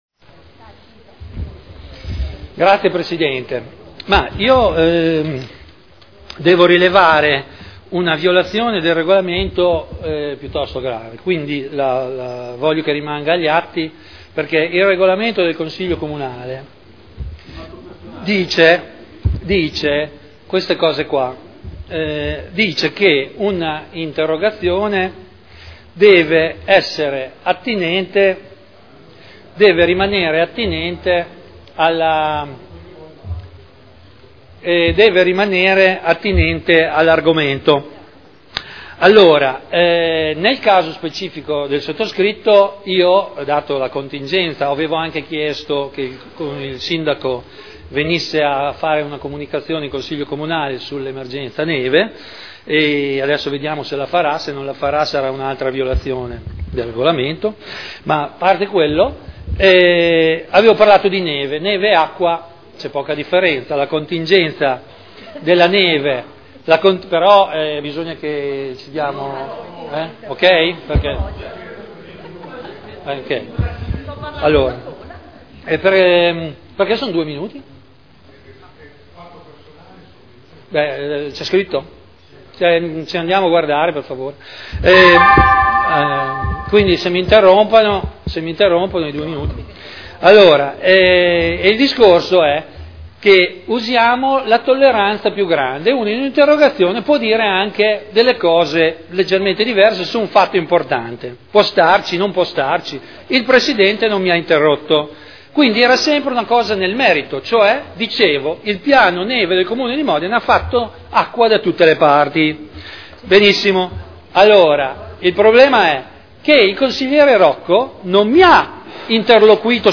Seduta del 6 febbraio Intervento per fatto personale